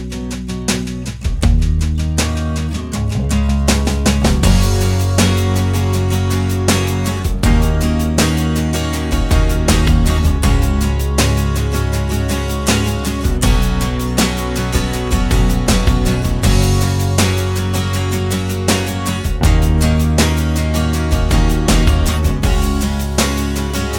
no Backing Vocals Duets 4:03 Buy £1.50